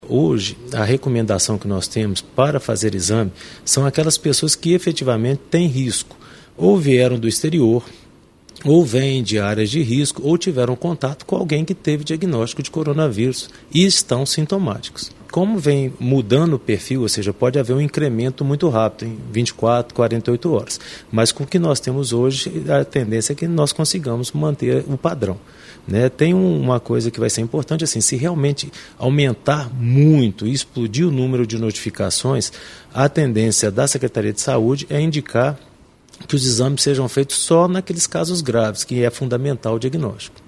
O Secretário de Estado de Saúde de Minas Gerais, Carlos Eduardo Amaral ressalta o público-alvo para que seja feito esses exames.